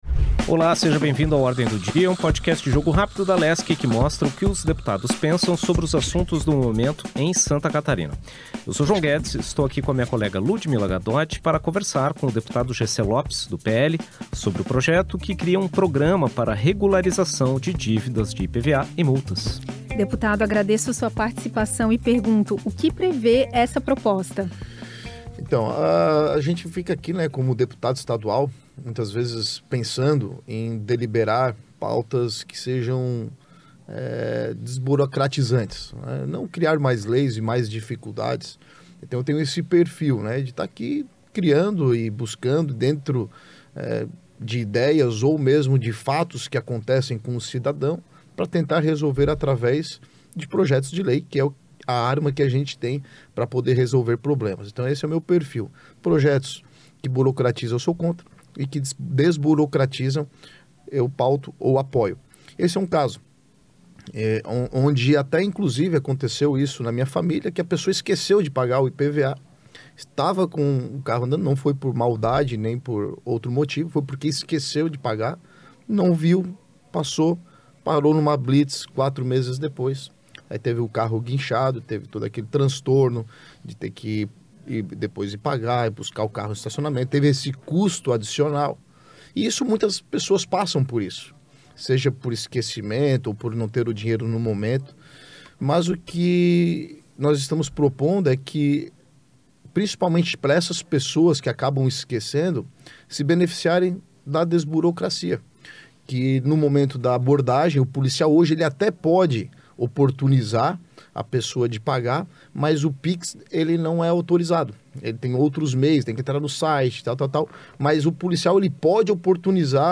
O deputado Jessé Lopes (PL) é o convidado do último episódio do ano do podcast de entrevistas da Alesc. O parlamentar falar sobre o projeto de lei de sua autoria que prevê a criação de um programa para regularização de dívidas de IPVA e multas em Santa Catarina.